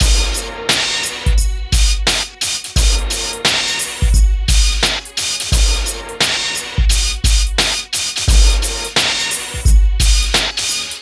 Index of /90_sSampleCDs/Best Service ProSamples vol.01 - Hip Hop and R&B Drumloops [AKAI] 1CD/Partition D/VOLUME 004